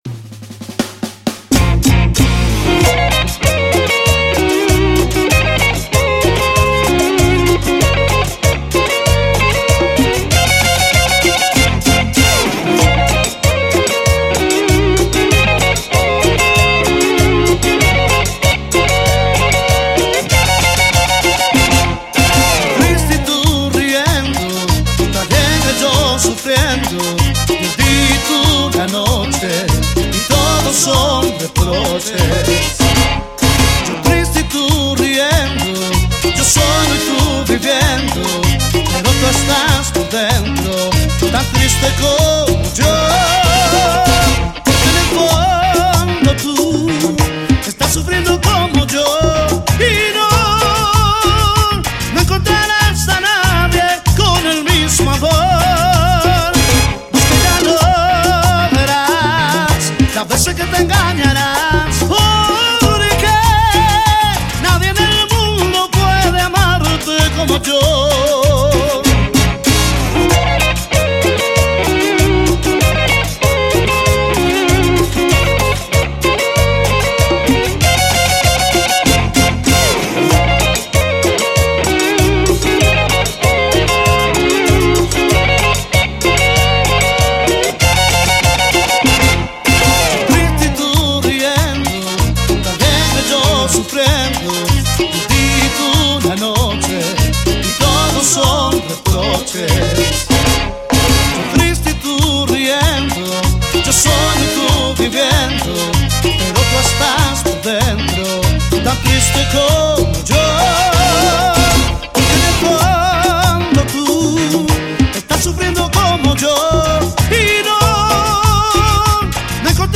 Genre Cumbia Latina